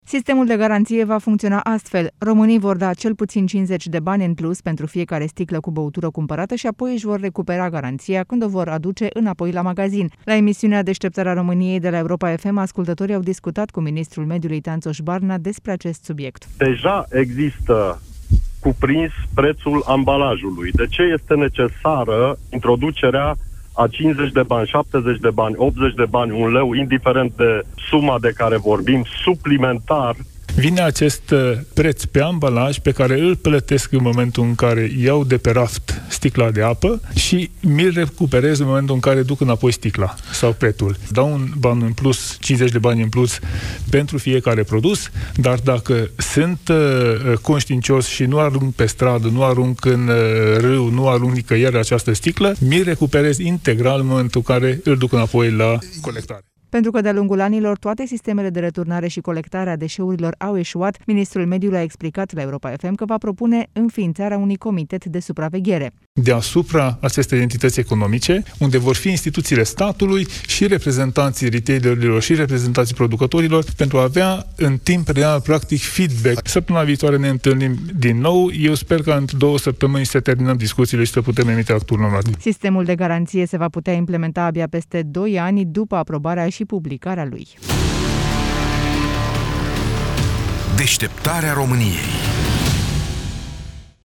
Discuțiile pe acest subiect trenează de mult timp pentru că modul de organizare și funcționare a pus probleme atât producătorilor cât și retailerilor, spune  ministrul mediului Tanczos Barna, în emisiunea Deşteptarea României, la Europa FM.
La emisiunea Desteptarea Romaniei de la Europa FM, ascultatorii au discutat cu ministrul Mediului despre acest subiect: